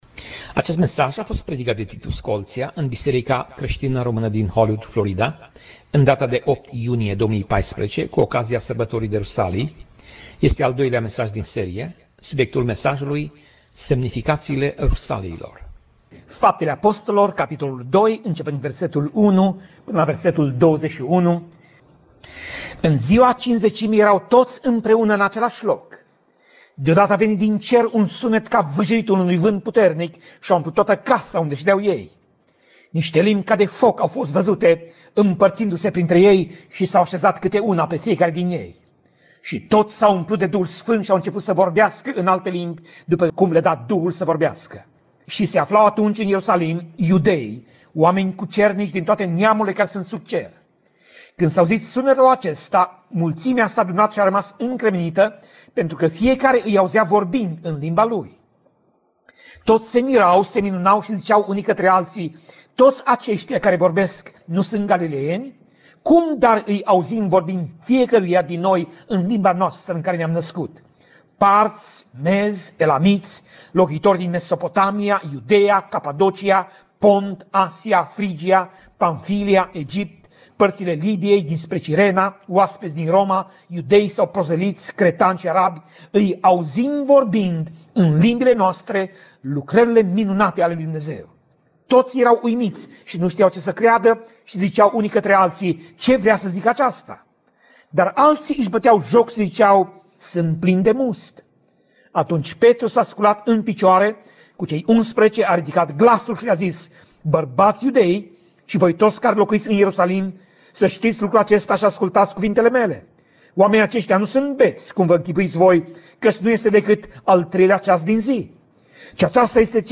Tip Mesaj: Predica